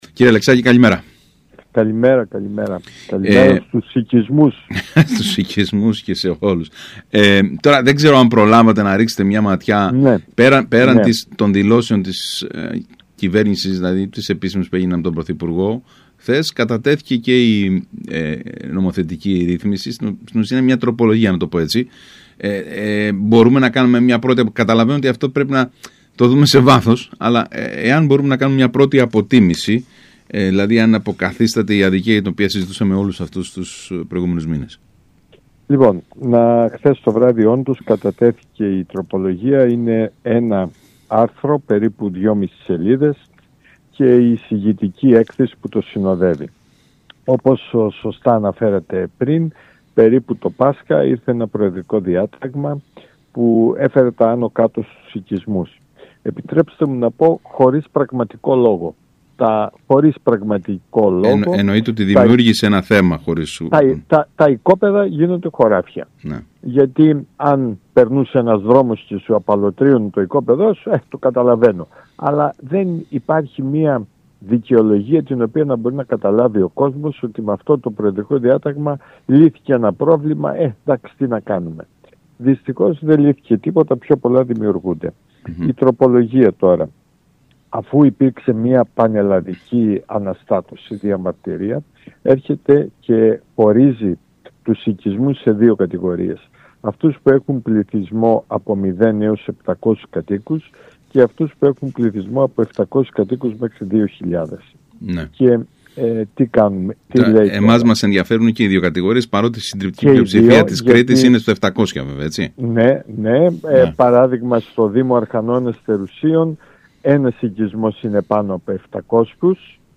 Ακούστε όσα είπε ο κ. Αλεξάκης στον ΣΚΑΪ Κρήτης: